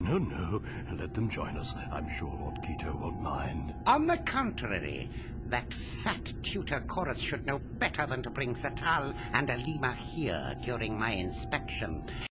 Chairman Bearus and Lord Keto — (audio)